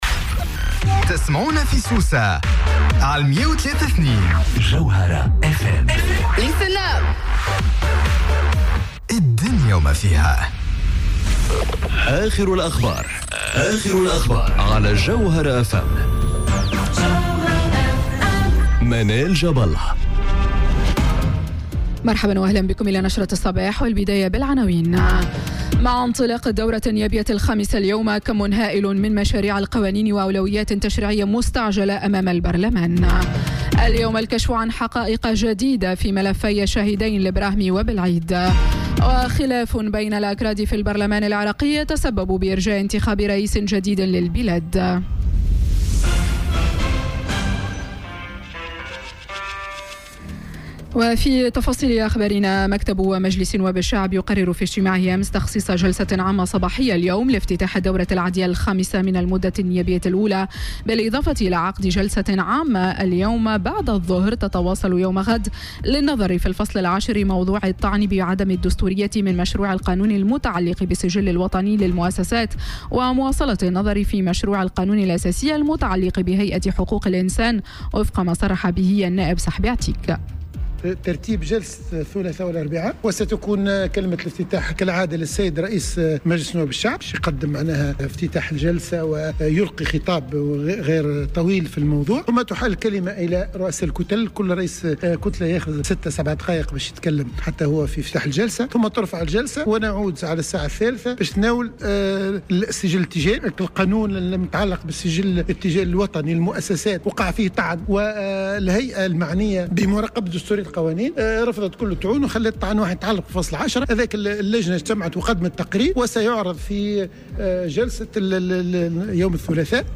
نشرة أخبار السابعة صباحا ليوم الثلاثاء 02 أكتوبر 2018